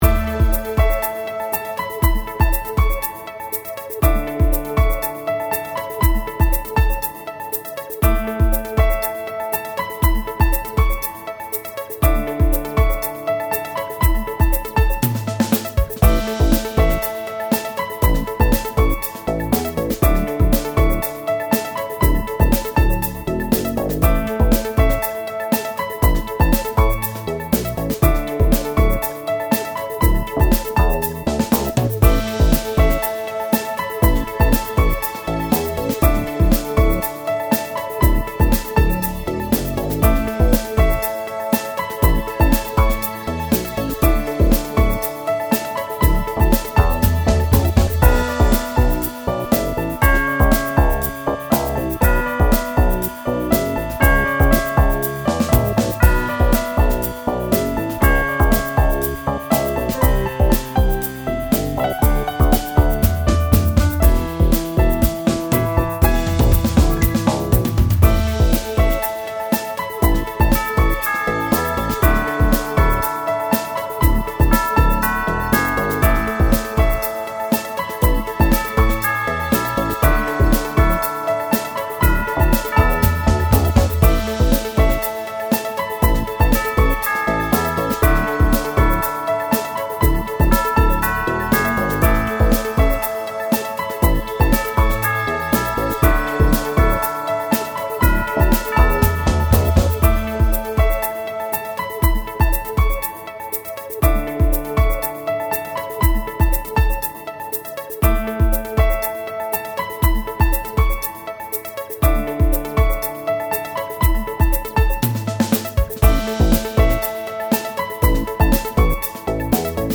軽快なテンポの爽やかなBGMです。
メインのピアノの旋律とシンセサイザーのアルペジオが印象的な楽曲となっております。
なお、本BGMはループ対応音源となっています。